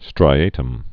(strī-ātəm)